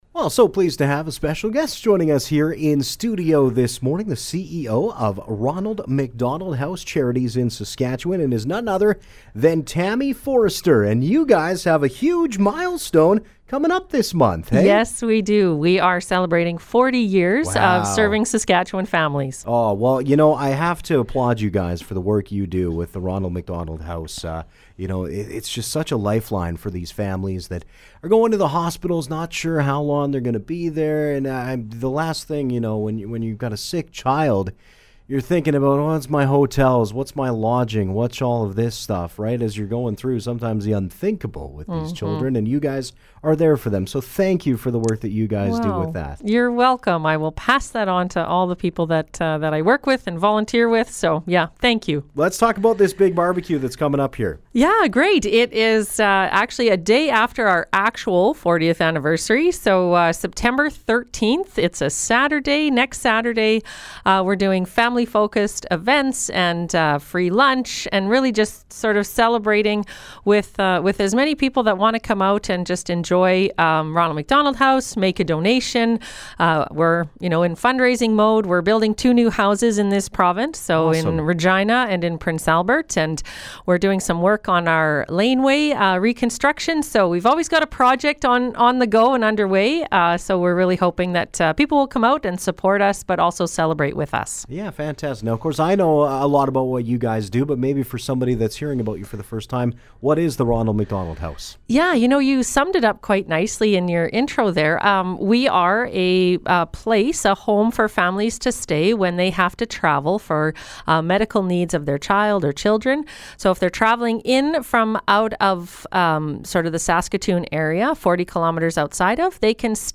Interview: Ronald McDonald House Charities
ronald-mcdonald-house-interview.mp3